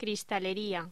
Locución: Cristalería
voz
Sonidos: Hostelería